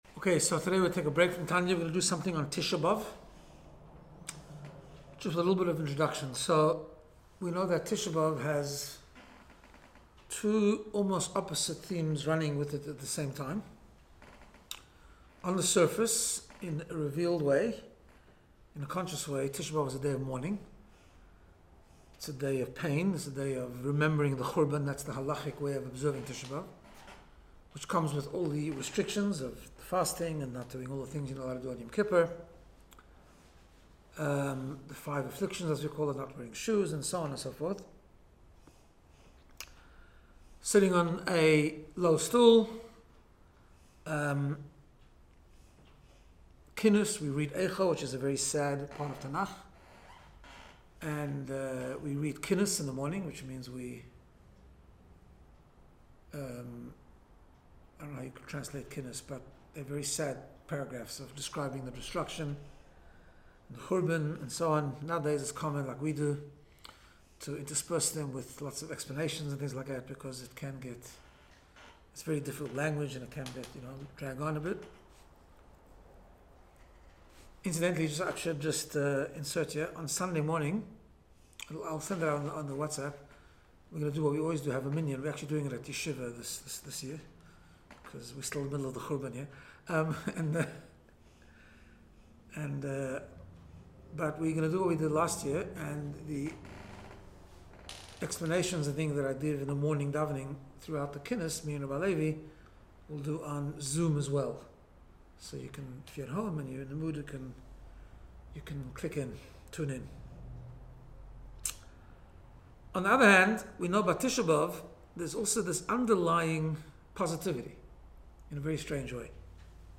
Special Tisha B'av Chassidus Shiur